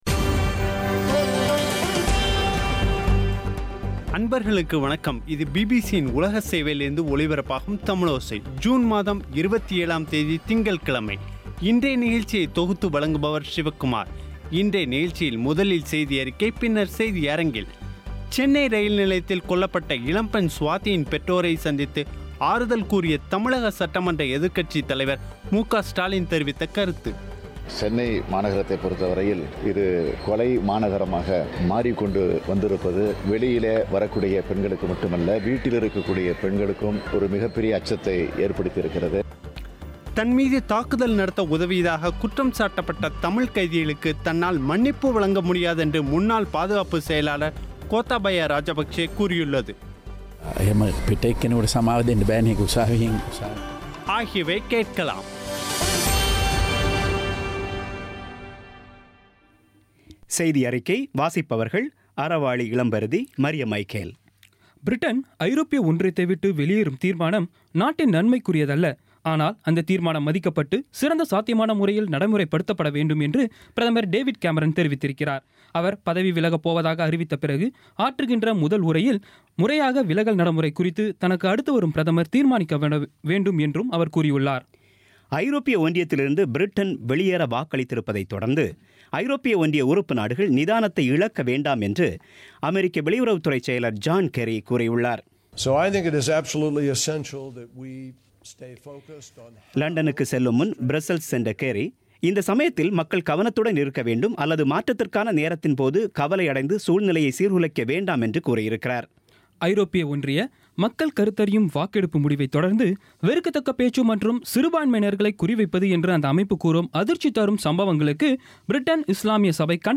இன்றைய நிகழ்ச்சியில் முதலில் செய்தியறிக்கை, பின்னர் செய்தியரங்கில்